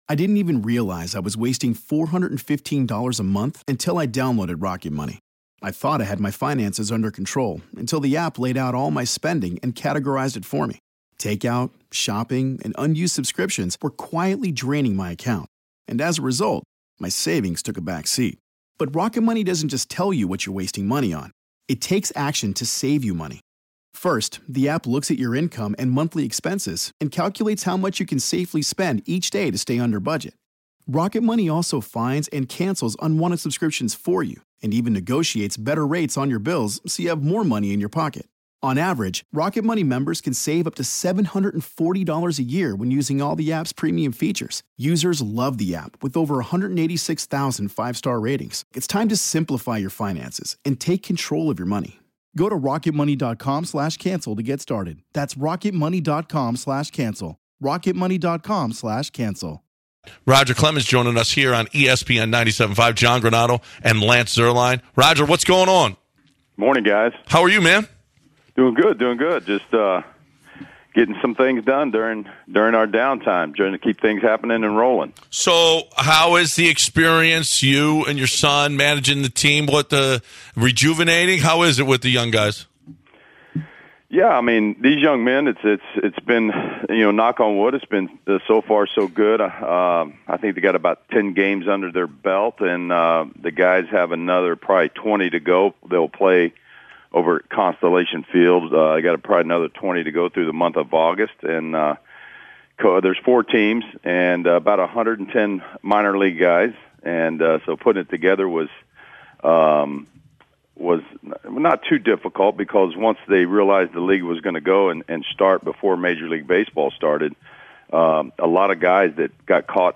Roger Clemens Interview